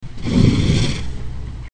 The multipurpose trunk can also be used to make noise, loud noise!
elephant-snort.mp3